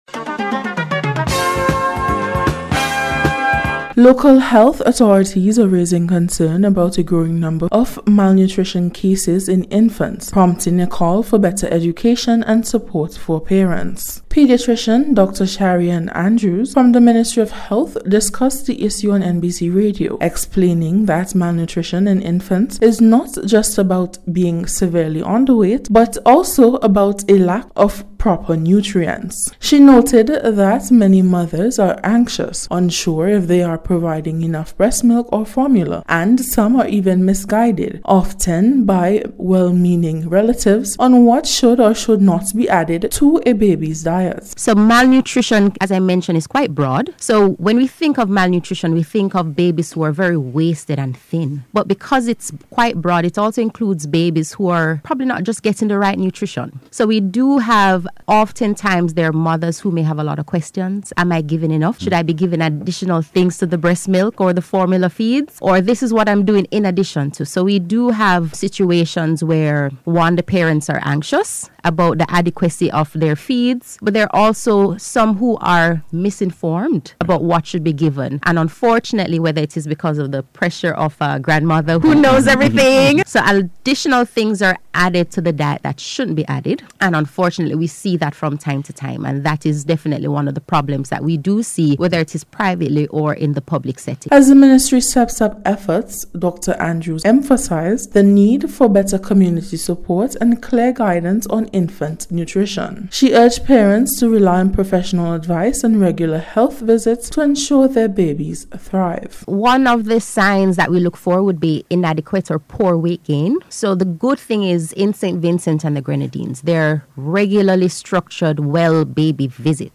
MALNOURISHED-BABIES-REPORT.mp3